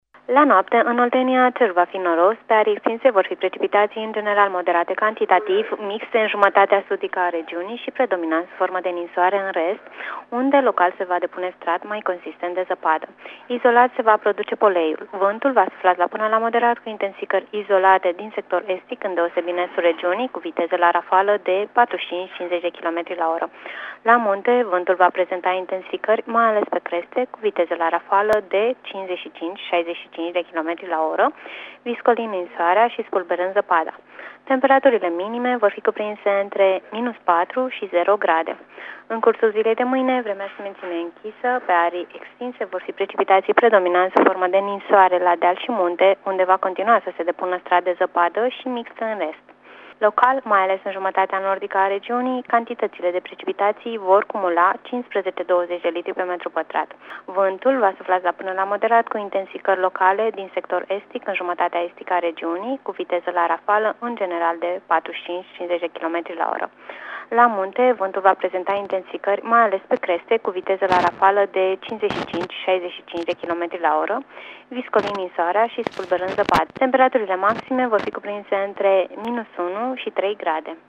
Prognoza meteo 14/15 decembrie (audio)